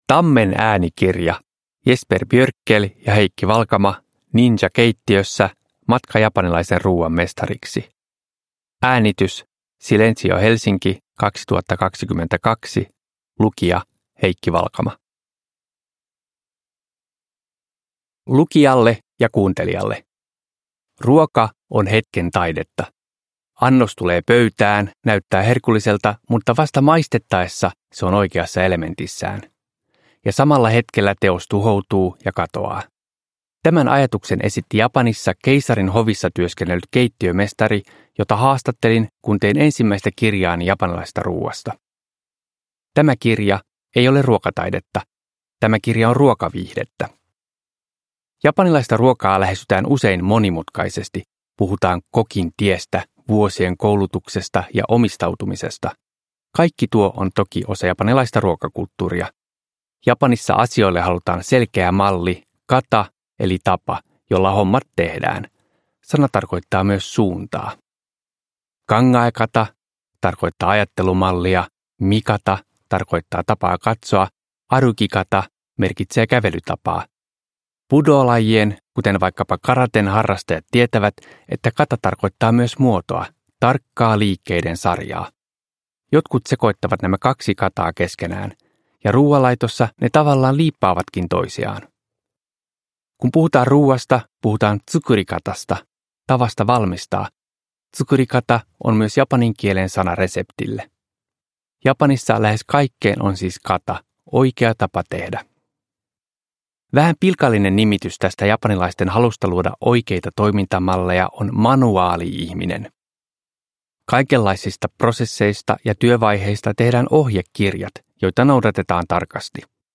Ninja keittiössä – Ljudbok – Laddas ner